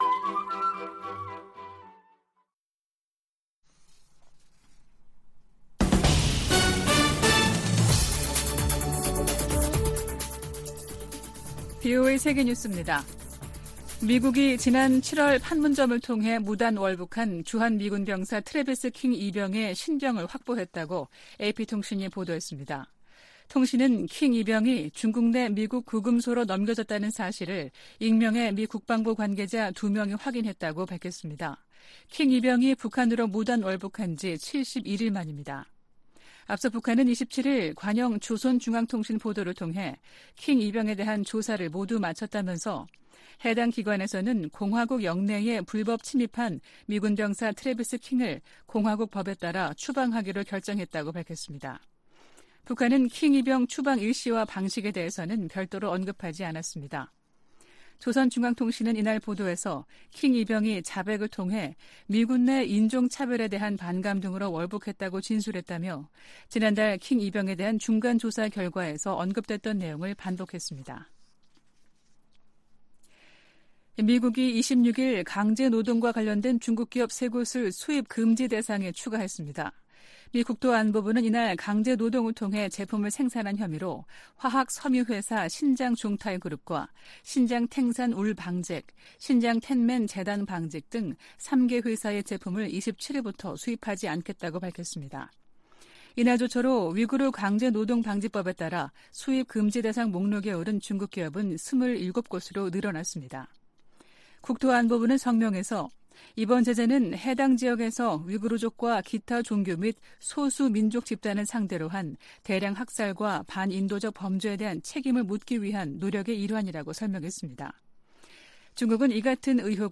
VOA 한국어 아침 뉴스 프로그램 '워싱턴 뉴스 광장' 2023년 9월 28일 방송입니다. 미 국무부는 미한 연합훈련을 '침략적 성격이 강한 위협'이라고 규정한 북한 유엔대사의 발언에 이 훈련은 관례적이고 방어적인 것이라고 반박했습니다. 한국의 신원식 국방부 장관 후보자는 9.19 남북 군사합의 효력을 최대한 빨리 정지하도록 추진하겠다고 밝혔습니다.